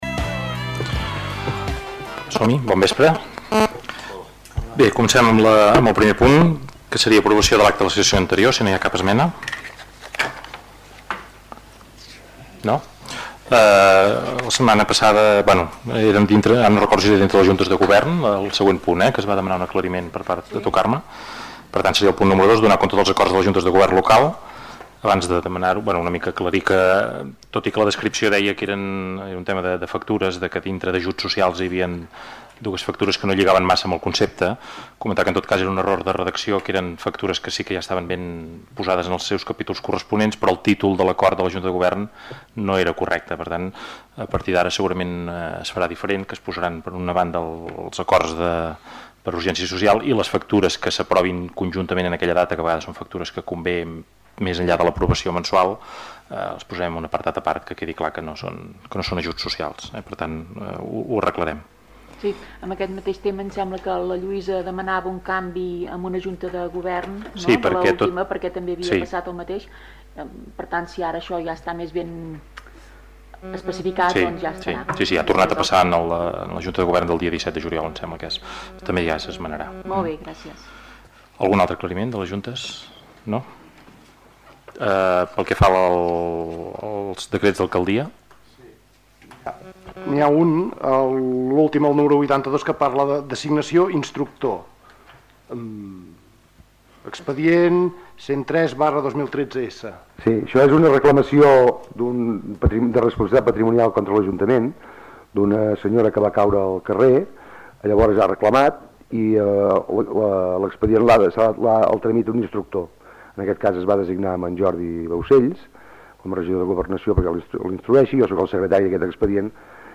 ple ordinari del mes de juliol que va ser de tràmit i que va durar poc més de sis minuts. Entre els punts de l'ordre del dia destaca l'aprovació inicial del Pla Especial Urbanístic d'Ordenació Volumètrica del Centre Residencial d'Acció Educativa (CRAE) La Serra de Taradell, un punt que va ser aprovat per unanimitat.